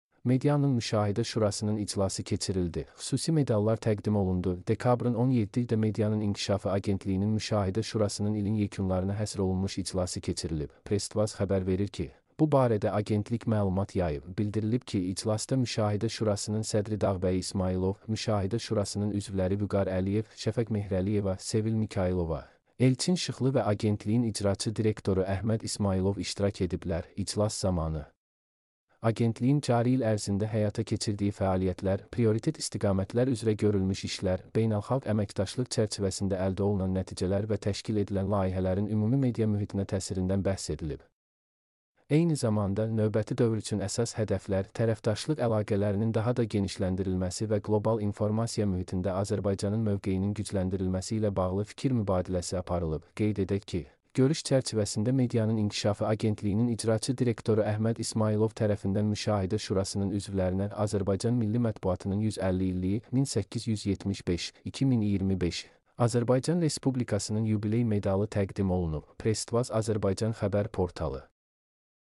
mp3-output-ttsfreedotcom-62.mp3